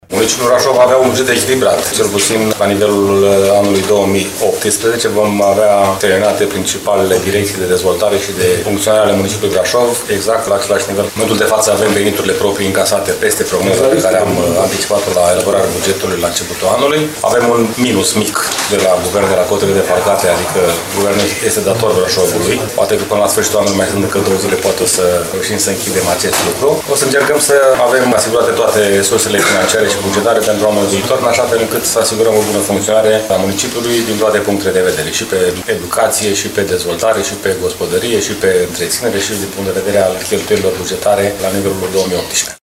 La ultima conferință de presă din acest an, primarul municipiului Brașov, George Scripcaru a arătat că bugetul pe anul 2018 va fi unul echilibrat, astfel că municipiul Brașov nu va avea probleme de funcționare: